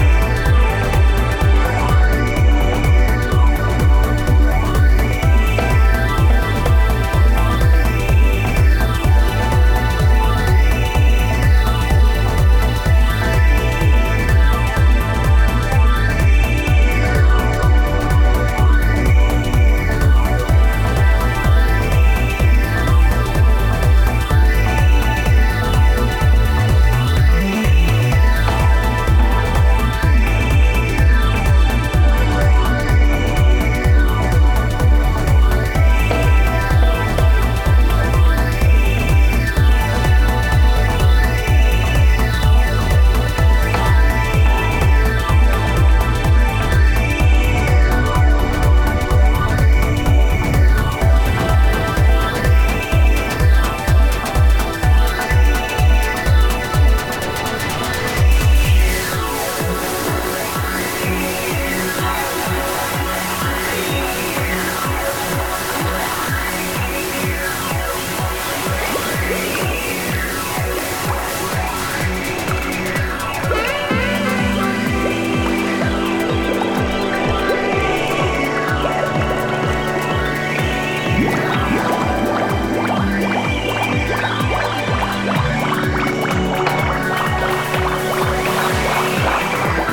トランシーな展開に持ってかれるスペイシー・テックハウス！気持ちイイ音の粒子もバレアリック感を演出。
CLUB